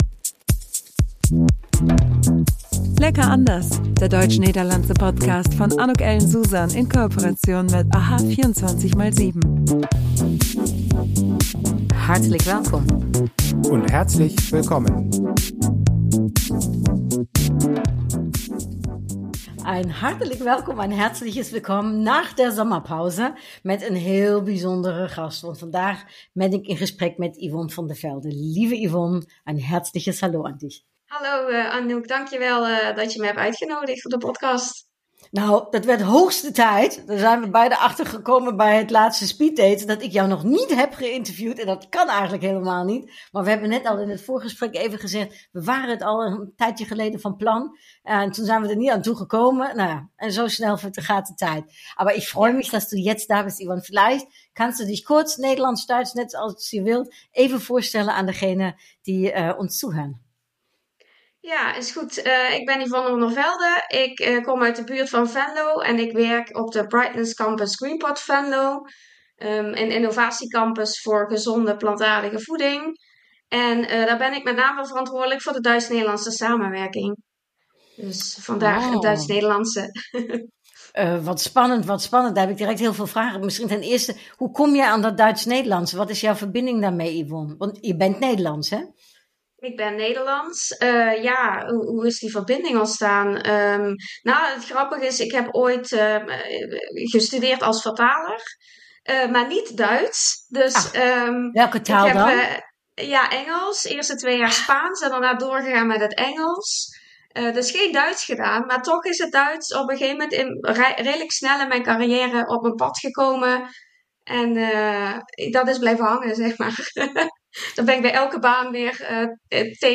We lachen, we zijn heel veel met elkaar eens, wir reden über Sinterklaas und Weihnachten, dem digitalen und auch dem LIVE DNL Speeddates in diesem Jahr.